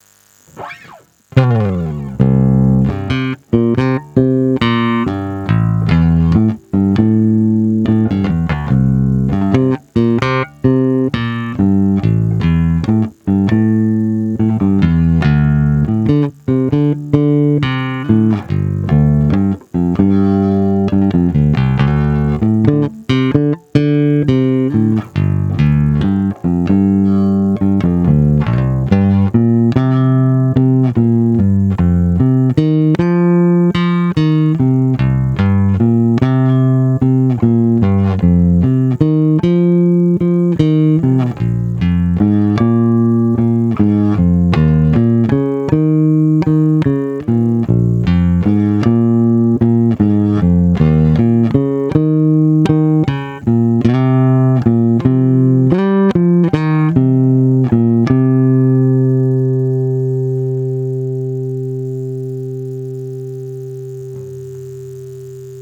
Nahrávky po úpravě se strunama Olympia:
Olympia nová oba na plno